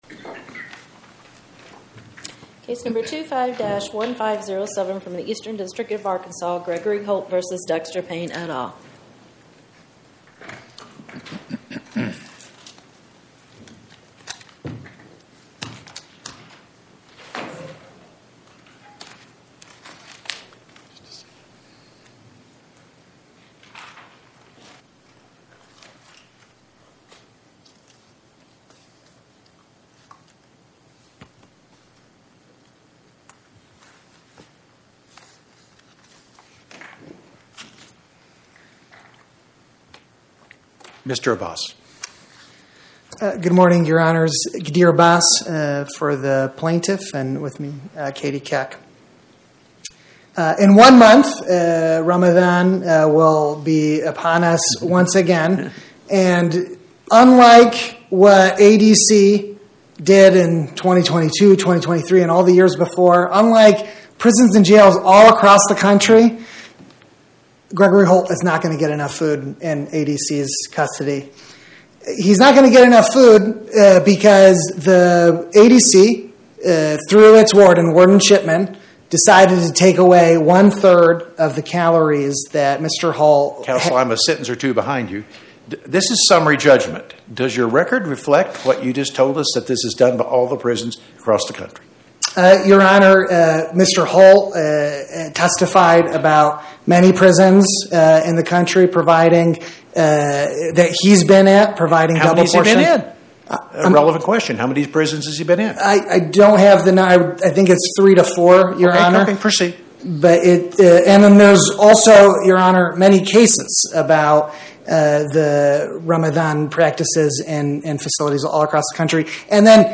Oral argument argued before the Eighth Circuit U.S. Court of Appeals on or about 01/15/2026